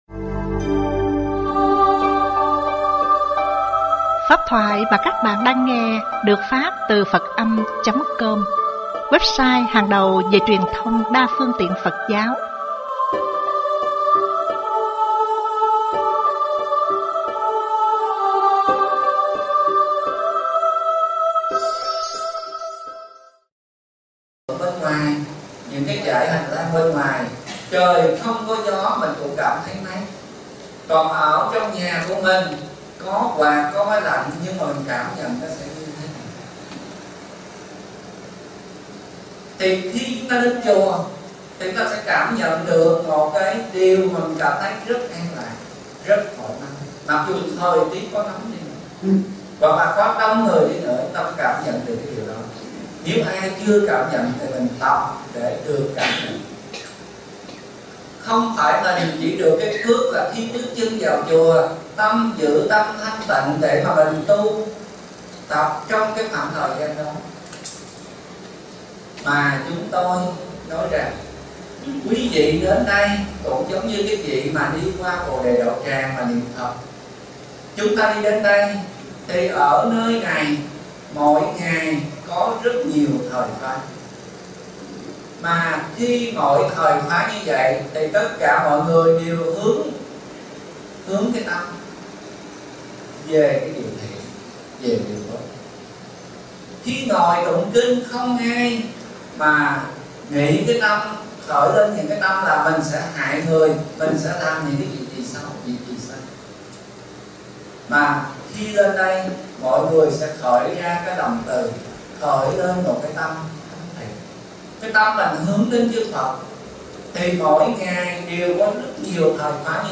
- Lớp Giáo Lý - ĐĐ.